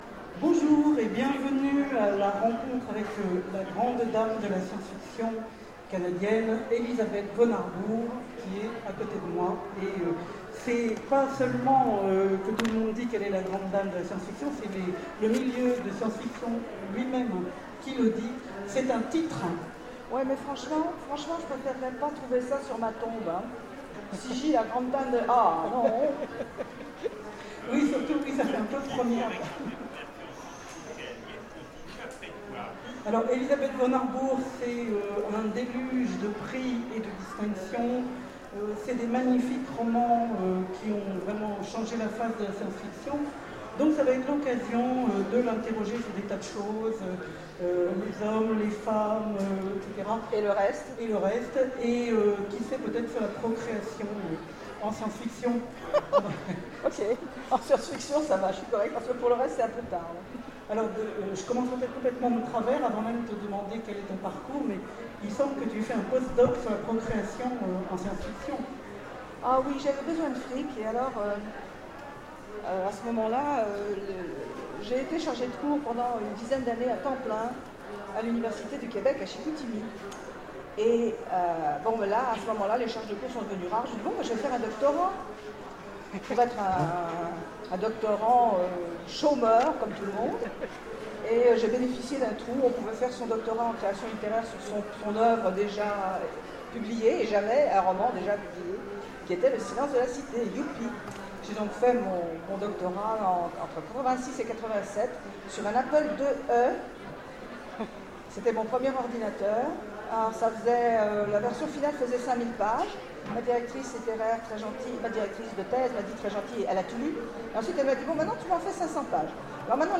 Utopiales 2014 : Rencontre avec Élisabeth Vonarburg
- le 31/10/2017 Partager Commenter Utopiales 2014 : Rencontre avec Élisabeth Vonarburg Télécharger le MP3 à lire aussi Elisabeth Vonarburg Genres / Mots-clés Rencontre avec un auteur Conférence Partager cet article